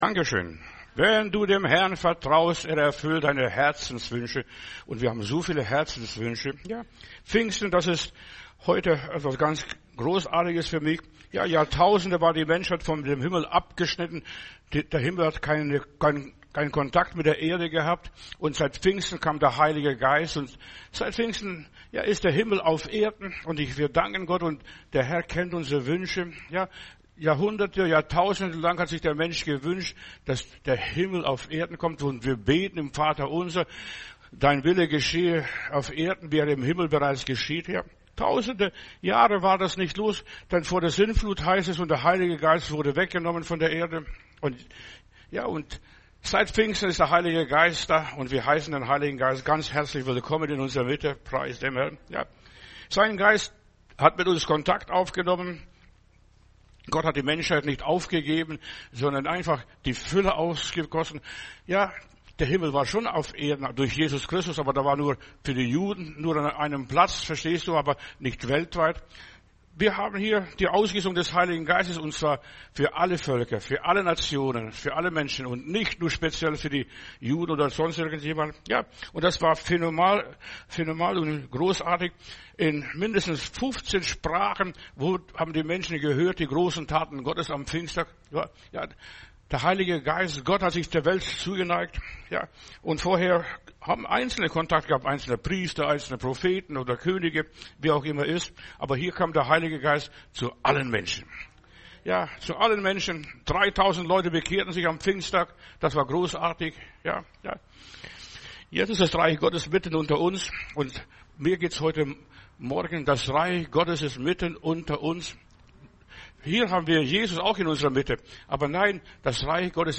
Predigt herunterladen: Audio 2025-06-08 Gottes Gegenwart genießen Video Gottes Gegenwart genießen